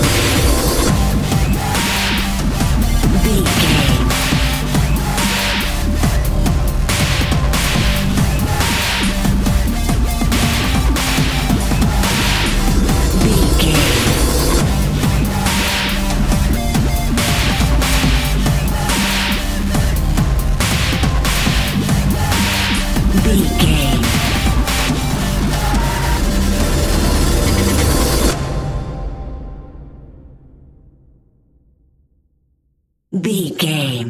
Aeolian/Minor
synthesiser
drum machine
orchestral hybrid
dubstep
aggressive
energetic
intense
strings
drums
bass
synth effects
wobbles
driving drum beat
epic